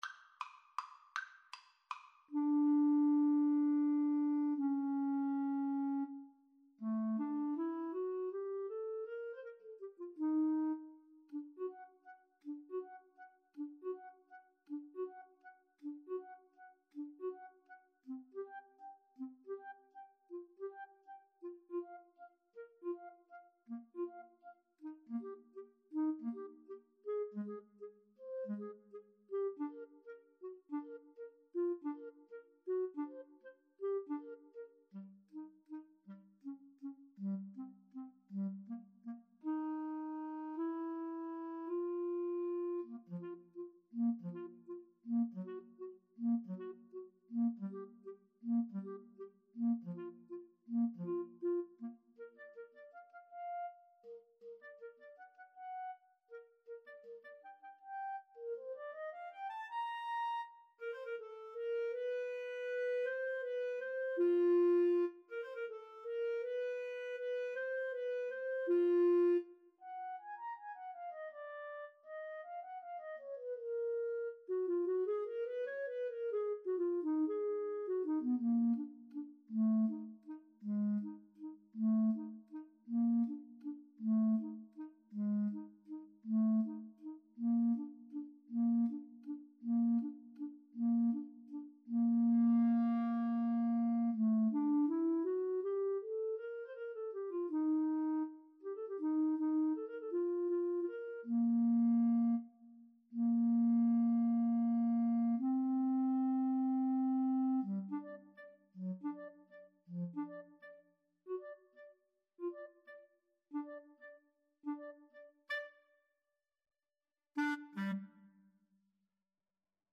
Allegretto = 160
3/4 (View more 3/4 Music)
Classical (View more Classical Clarinet Duet Music)